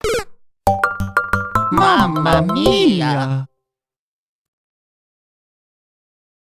An arrangement
Fair use music sample